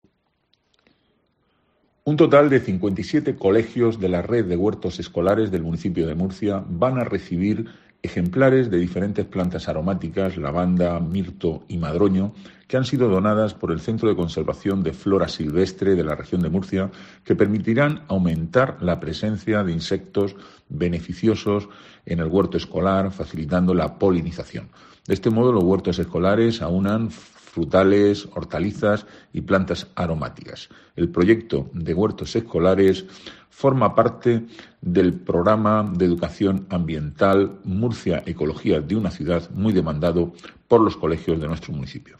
Antonio Navarro, concejal de Planificación Urbanística, Huerta y Medio Ambiente